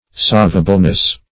Savableness \Sav"a*ble*ness\, n. Capability of being saved.